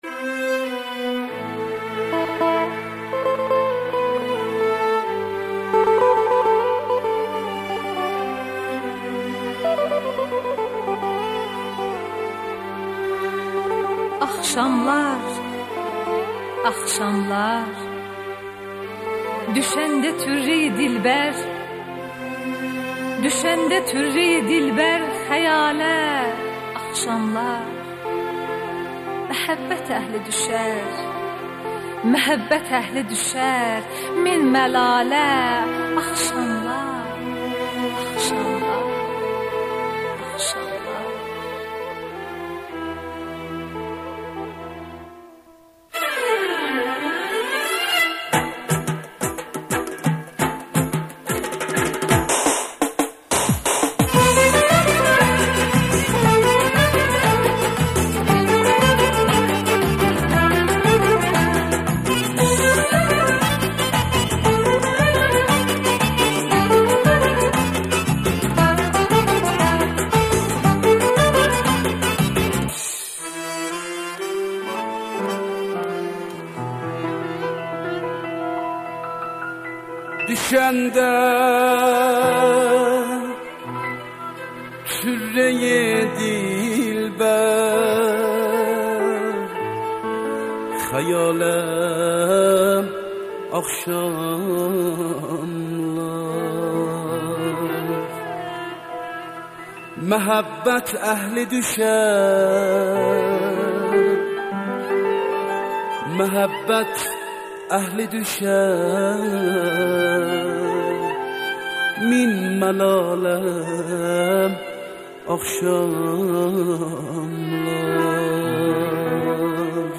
موسیقی آذری